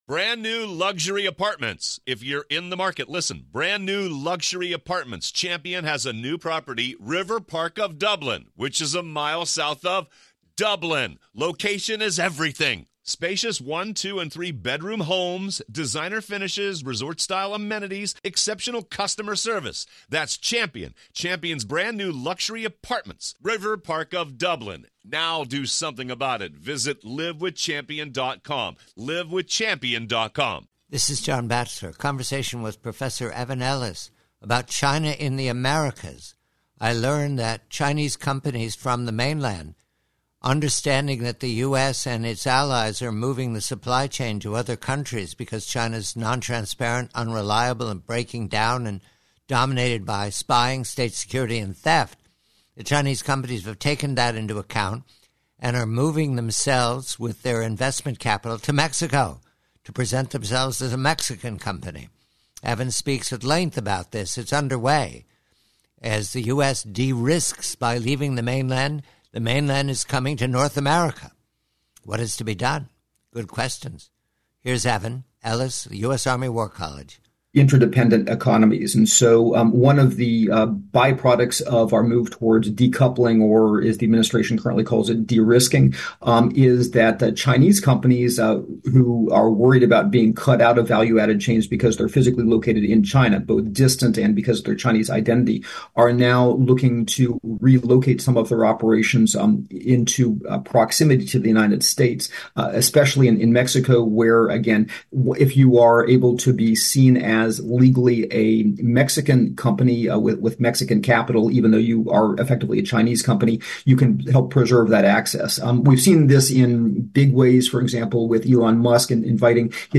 PREVIEW: Excerpt from a long conversation